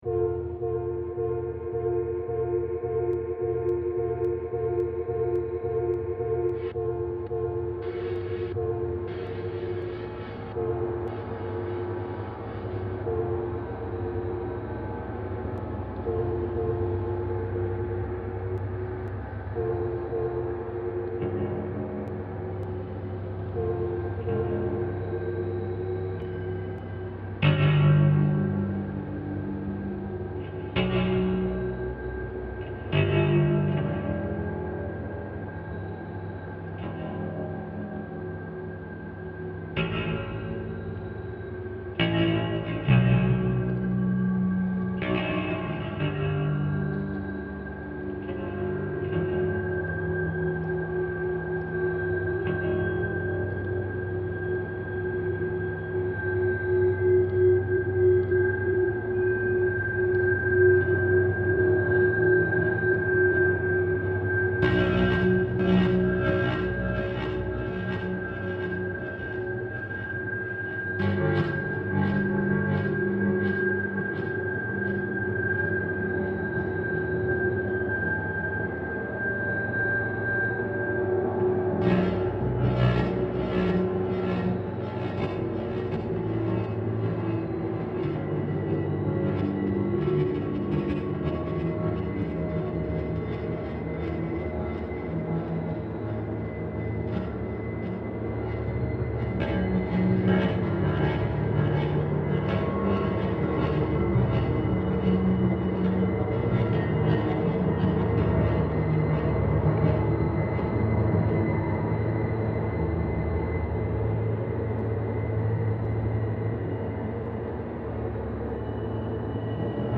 Toowoomba coal train reimagined